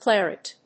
音節clar・et 発音記号・読み方
/klˈærət(米国英語)/